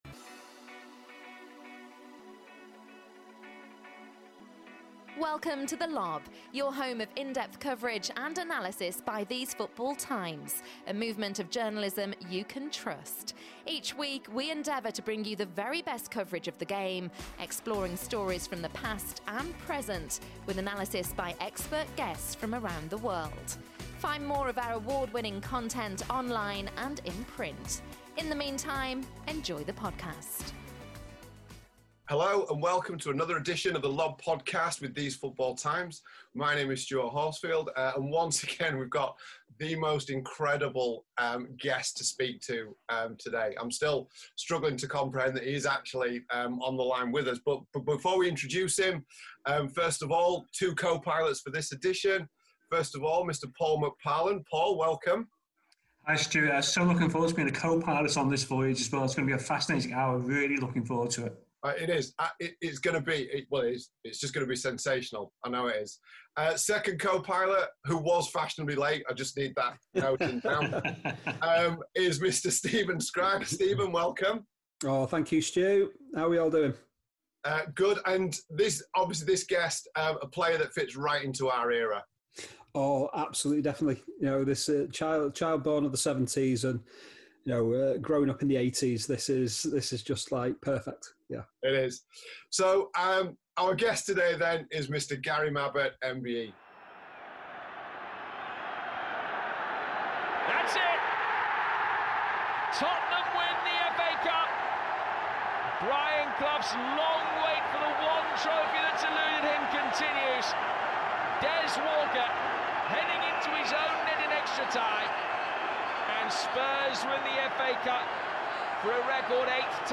Former Tottenham captain and club icon Gary Mabbutt joins us to talk all things Spurs, FA Cup, Gazza and living life as an elite footballer with diabetes.